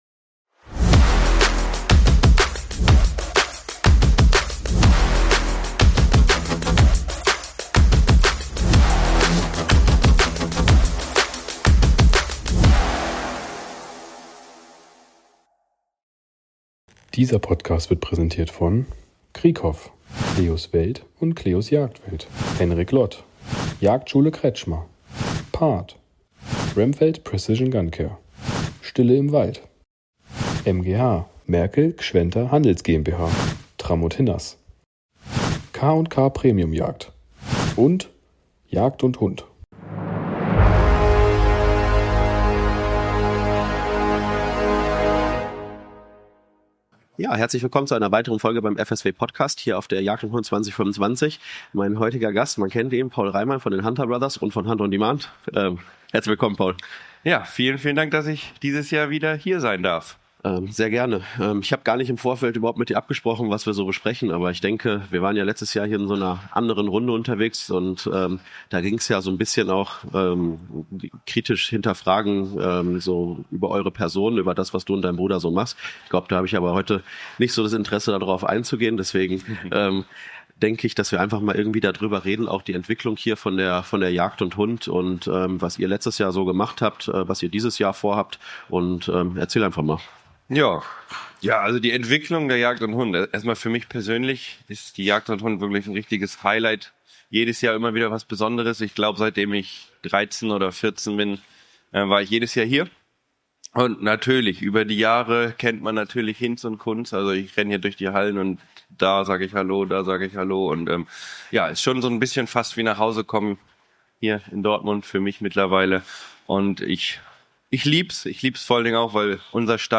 Wir sind live auf Europas größter Jagdmesse unterwegs und sprechen mit spannenden Gästen aus der Jagdszene. Ob Experten, Hersteller oder passionierte Jäger – in unseren Interviews gibt’s exklusive Einblicke, spannende Neuheiten und echte Insider-Talks rund um die Jagd.